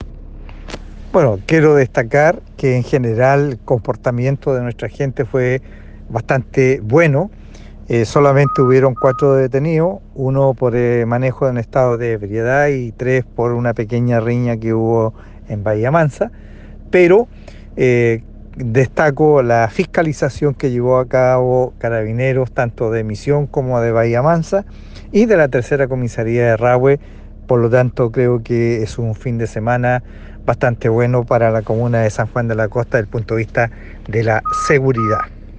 El alcalde Candia destacó la tranquilidad en que se desarrolló el “fin de semana XL”.